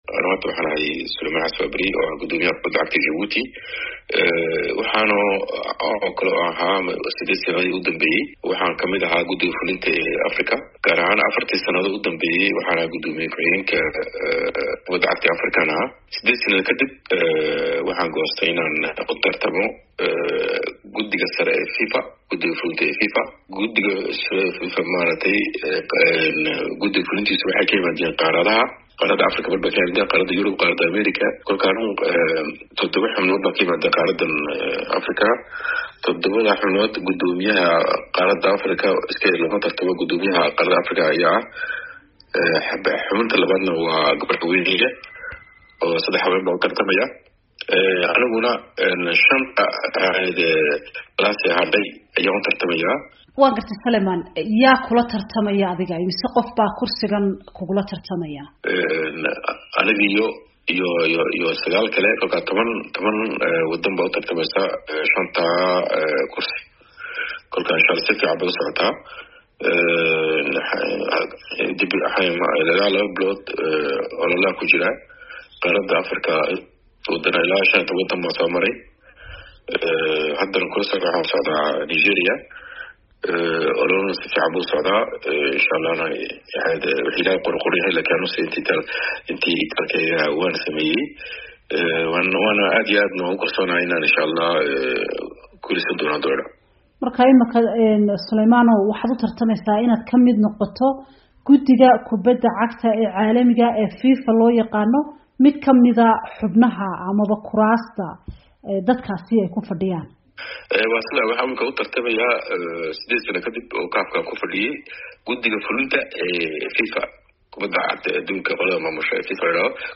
Wareysi: Suleyman Xasan Waaberi oo u sharaxay xubin guddiga FIFA ah